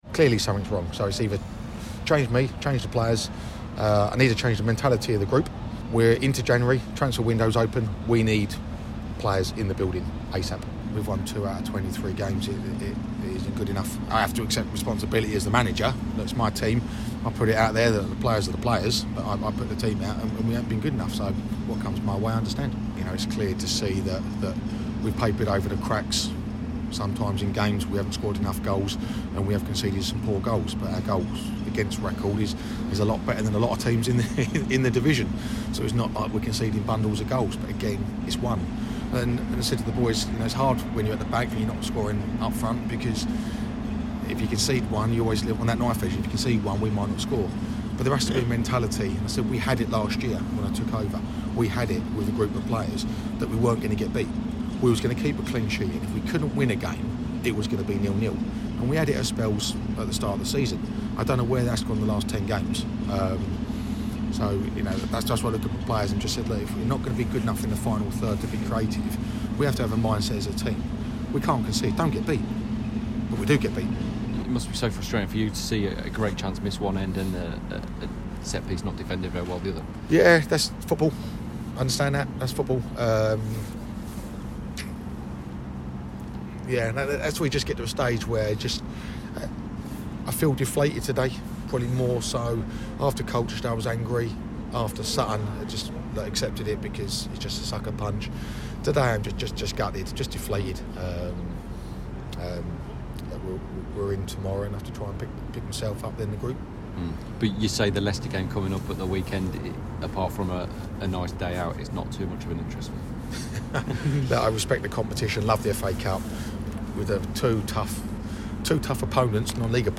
Manager Neil Harris spoke to us after the match.